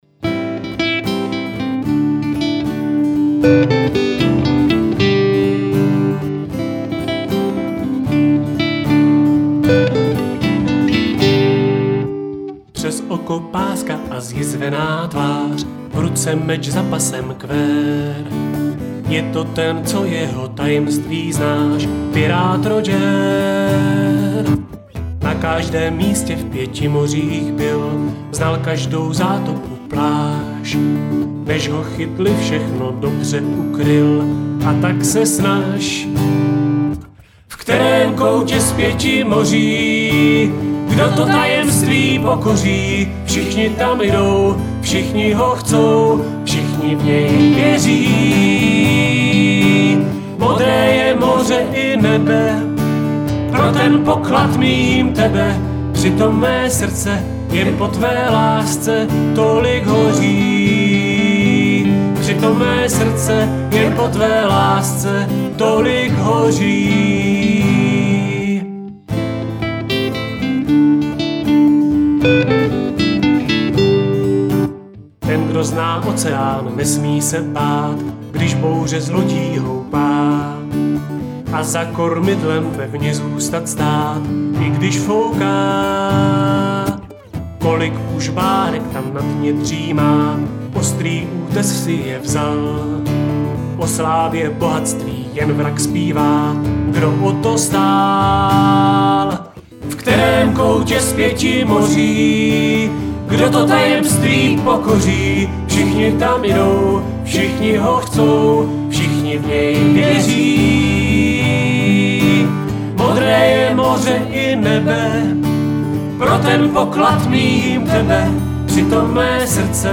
Táborová hymna
Zpravidla ji umí zpívat všechny děti, protože je vždy textově i hudebně jednoduchá.